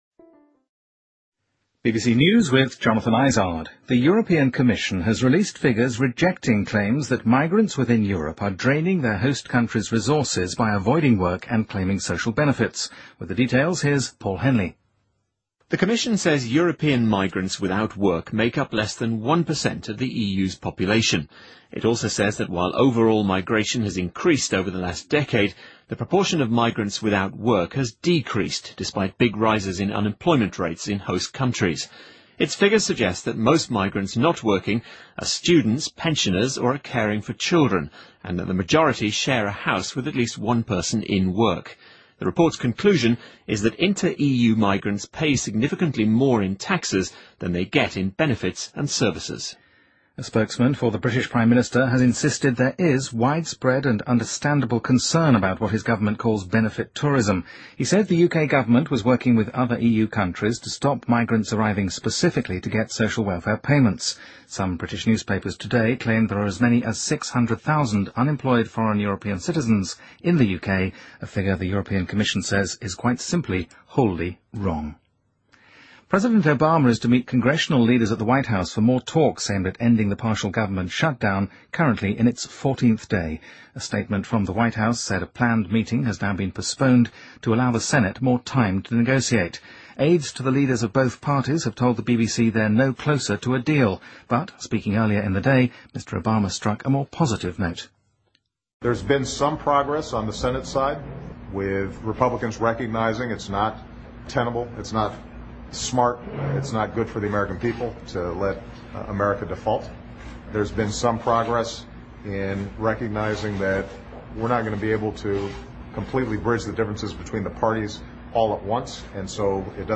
BBC news,人们对英国政府所谓的福利旅游表现出普遍的担心